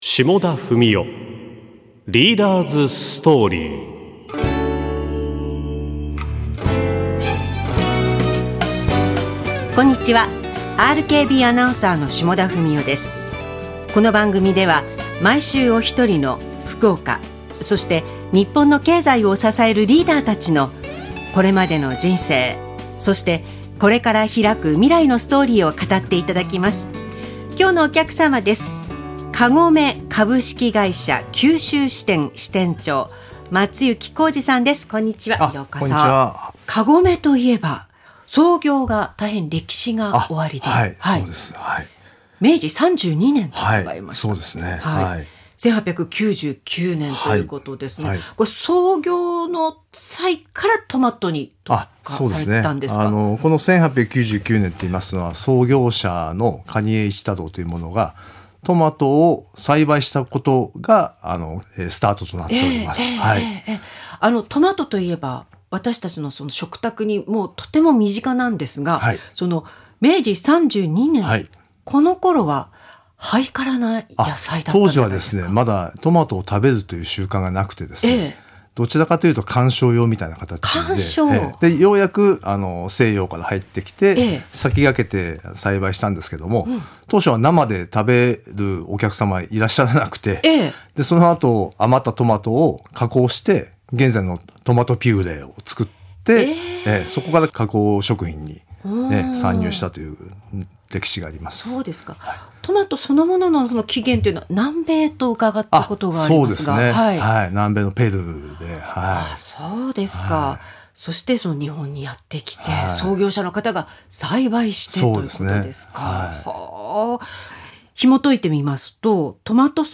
ラジオ